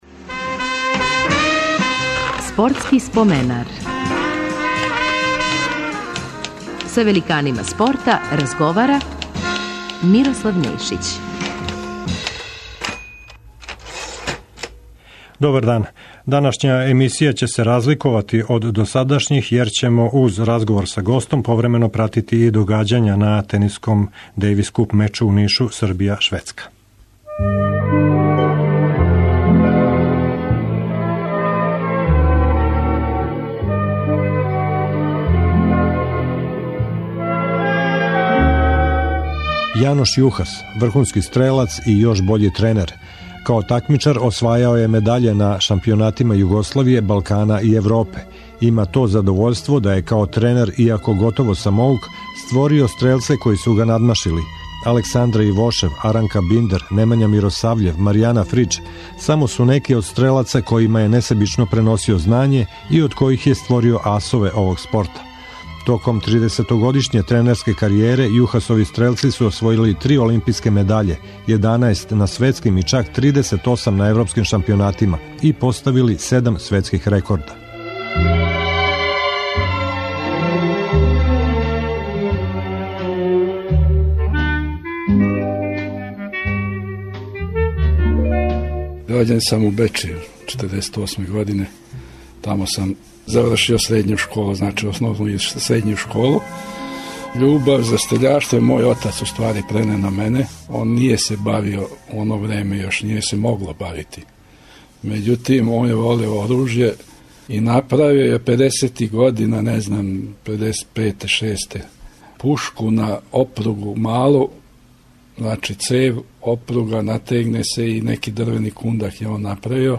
Спортски споменар је посвећен историји нашег спорта. Говорећи о својим почецима, успонима и падовима, поразима и победама, највећим успесима, истакнути спортисти који су се тамичили у протеклих седам деценија стварају слику спорта на овим просторима.